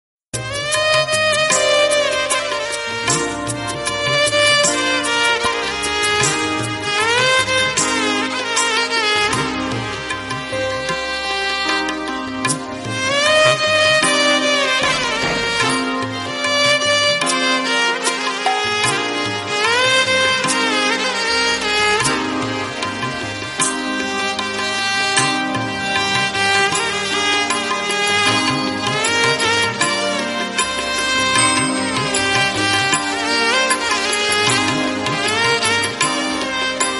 Telugu Ringtonedevotional ringtonemelody ringtone